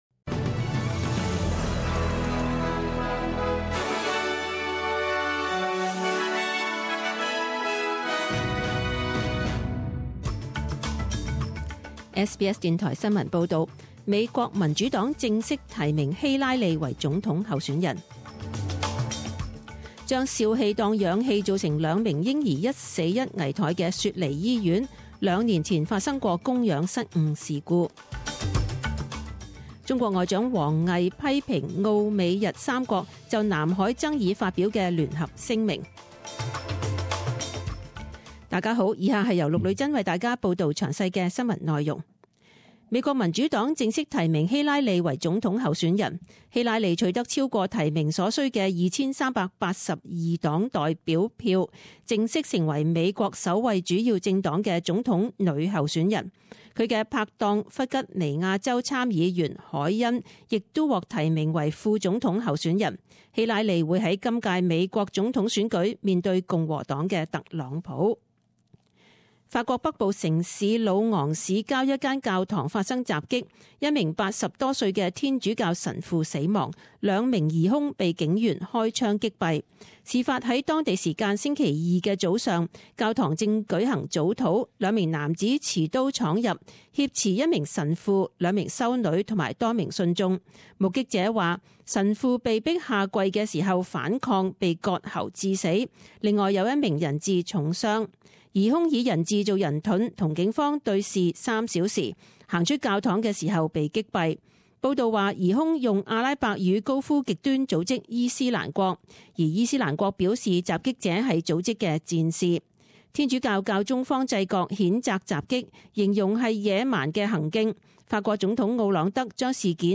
十点钟新闻报导 （七月二十七日）